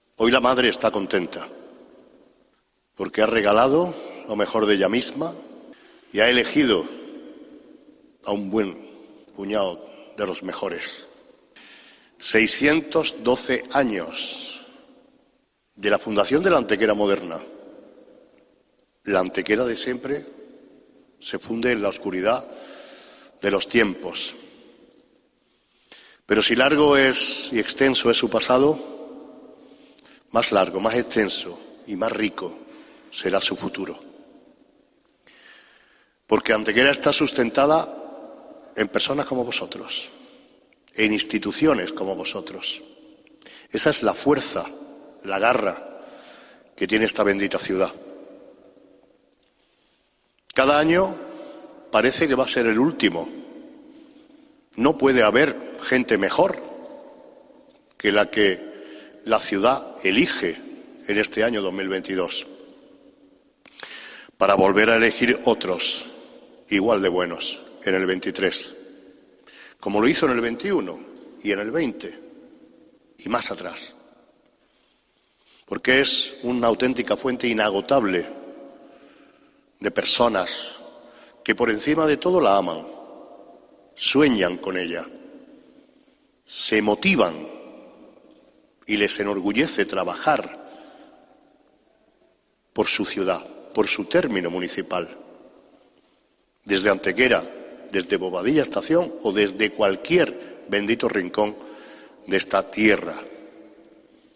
Tras las respectivas entregas de los galardones, tomó la palabra el Alcalde para cerrar el acto, agradeciendo y destacando la insigne labor que realizan todas las personas y empresas galardonadas en pro de su ciudad: "Hoy la madre está contenta.
Cortes de voz